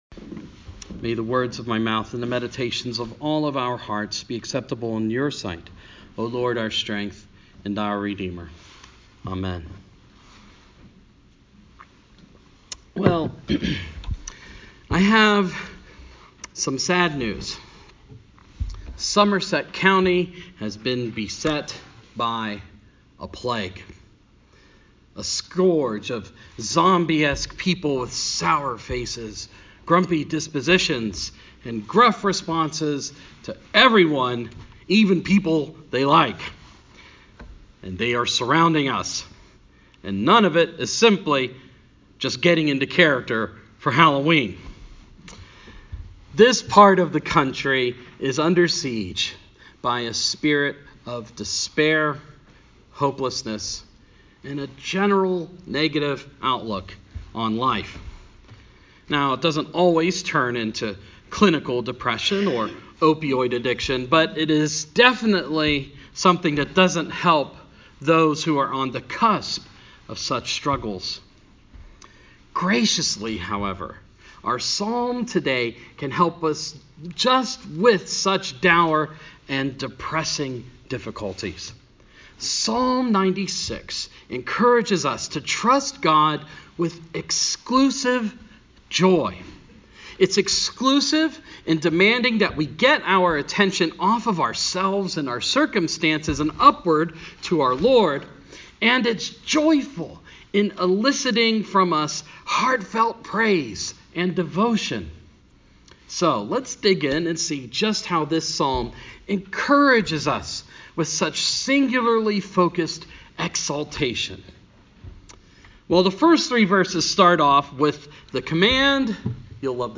Sermon – Proper 24 – 2017